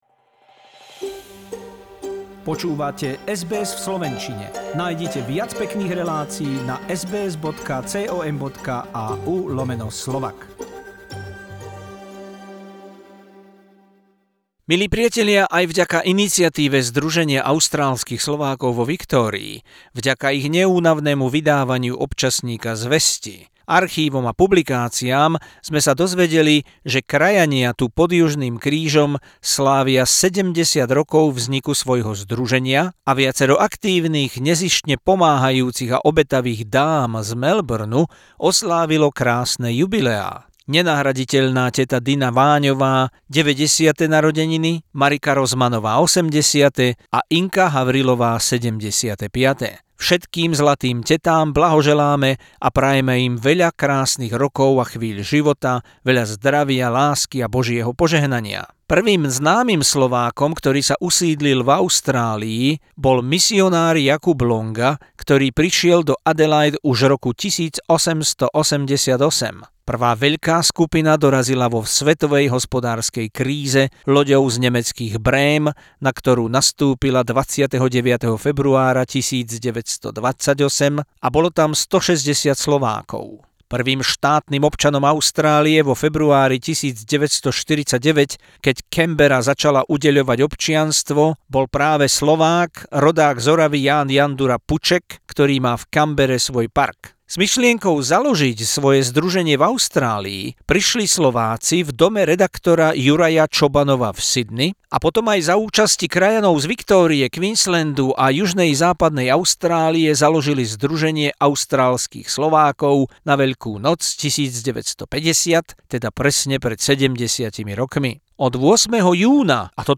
Exclusive SBS interview.